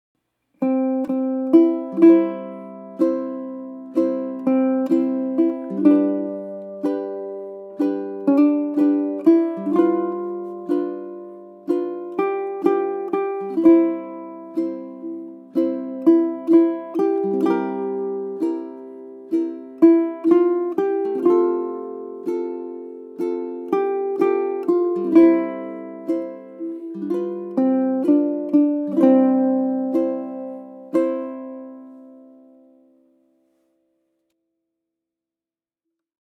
On the audio track I use the Simple Strum 4/4 the first time through: one thumb strum per measure on beat one: strum-2-3-4 | strum-2-3-4 | etc. On the repeat I increase strum frequency to two strums per measure, i.e., Down 2X Strum.
Down 2X Strum | Begin strumming after the pickup notes.